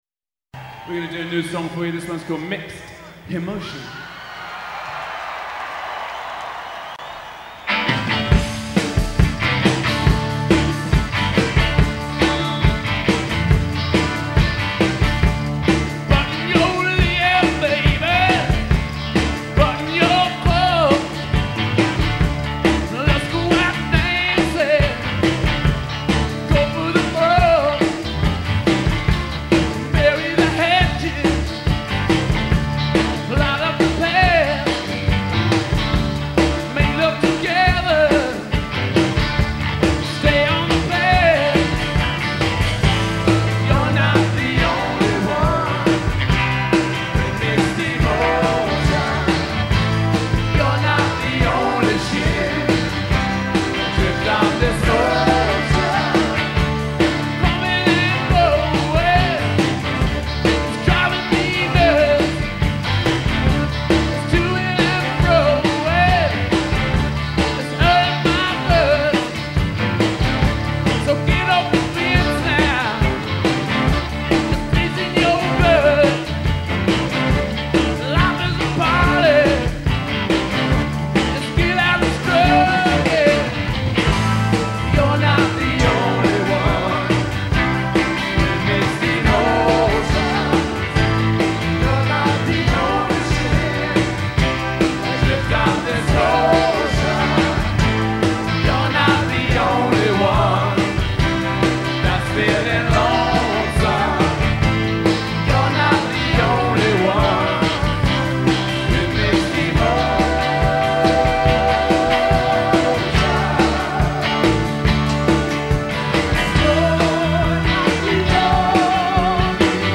live from the start of that momentous tour